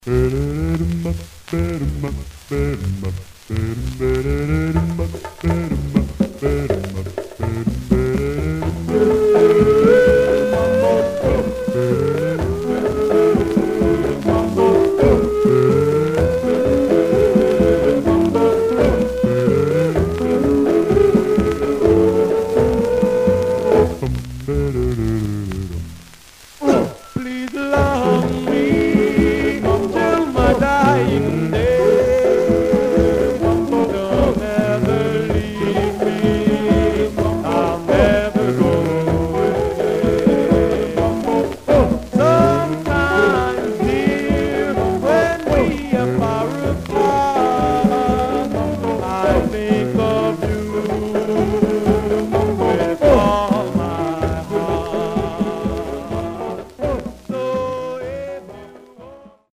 Much surface noise/wear
Mono
Male Black Groups